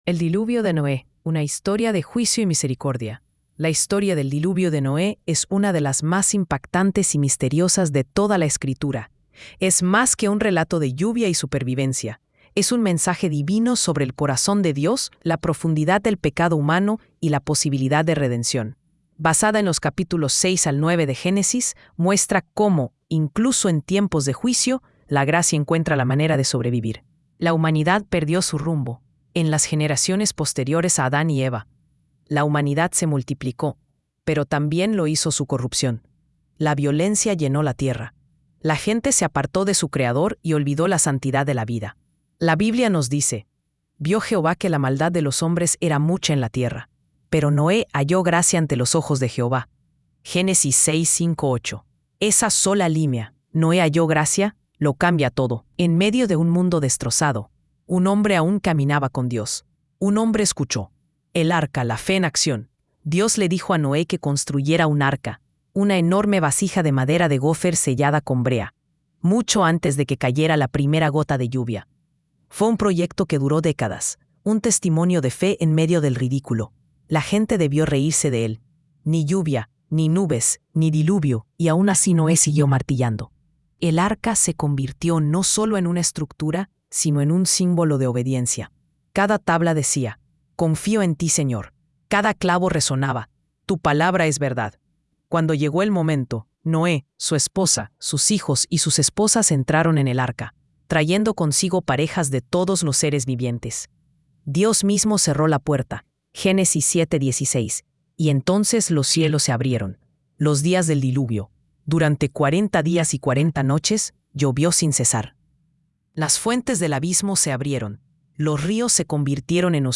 Cuando el Cielo Se Abrió” es una poderosa canción de adoración que revive la historia de Noé desde una perspectiva profundamente cristiana. Con un tono cinematográfico y una sensibilidad espiritual intensa, la canción narra la corrupción del mundo, la obediencia silenciosa de Noé, la construcción del arca, el estallido del Diluvio y el renacer de la esperanza con la paloma y el arco iris.